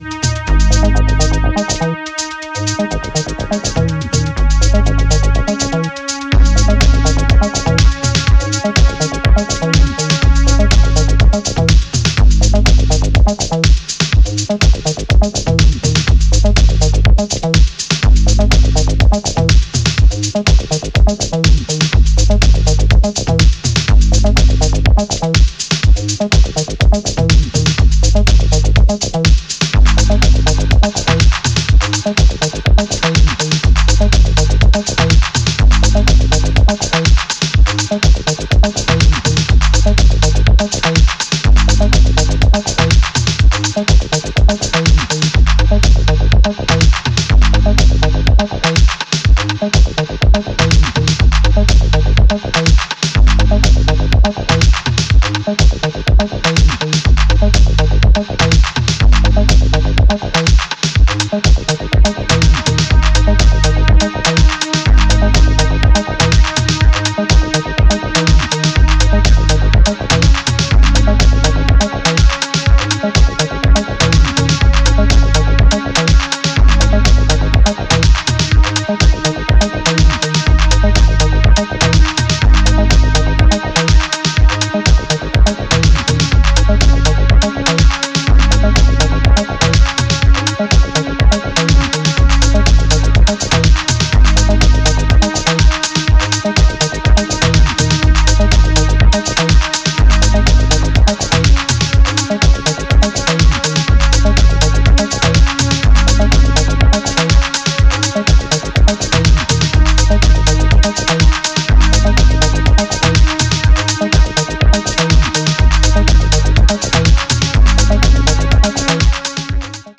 いずれの楽曲も深い時間に効力を発揮しそうな、抜群の仕上がりです！